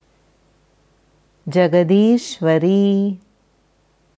Jagadishvari जगदीश्वरी jagad-īśvarī Aussprache
Hier kannst du hören, wie das Sanskritwort Jagadishvari, जगदीश्वरी, jagad-īśvarī ausgesprochen wird: